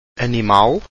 Category: Pronunciation
animal